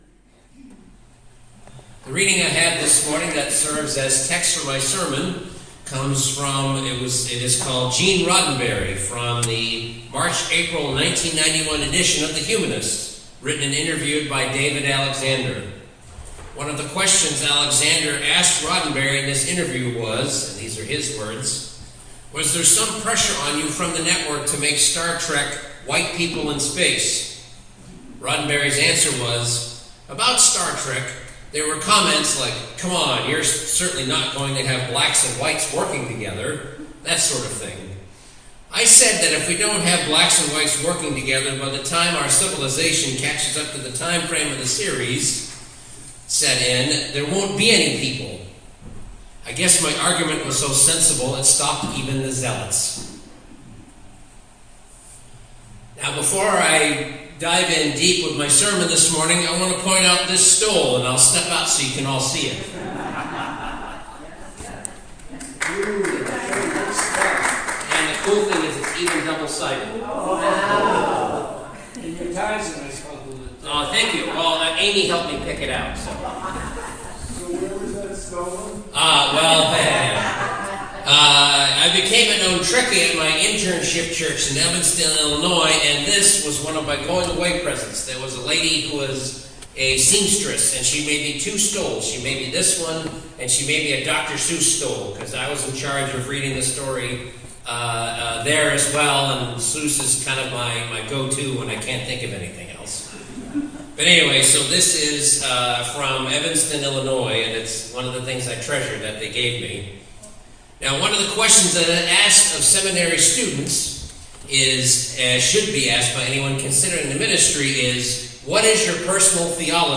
Sermon: The Changing Theology of Gene Roddenberry